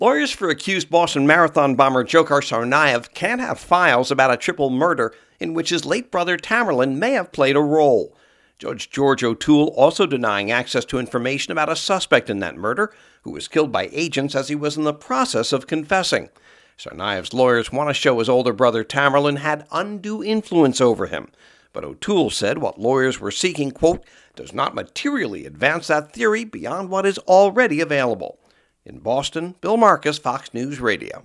HAS MORE FROM BOSTON.